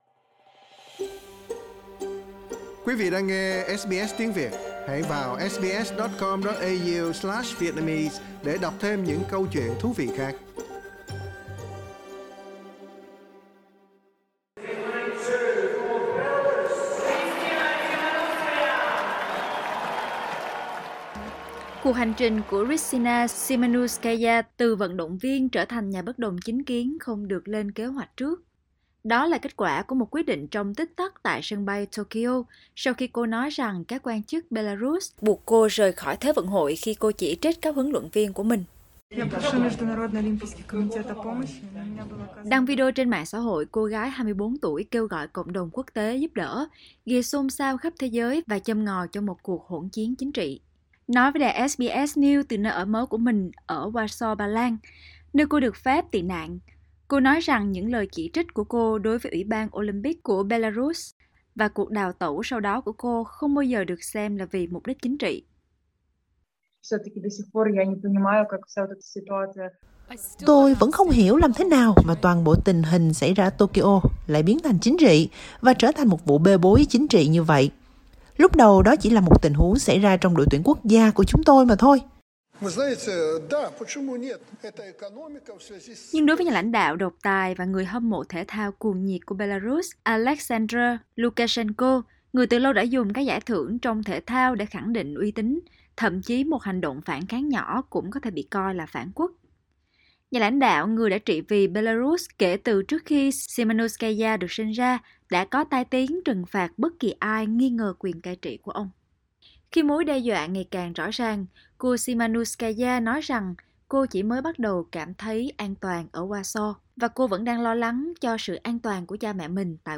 Vận động viên người Belarus đào tẩu khi thi đấu tại Thế vận hội Olympic Tokyo đã tuyên bố sẽ tiếp tục lên tiếng bảo vệ người dân Belarus. Trong cuộc phỏng vấn duy nhất tại Úc, Krystsina Tsimanouskaya đã nói với SBS News rằng chính phủ độc tài của đất nước có tiền sử đe dọa và ép các vận động viên phải im lặng.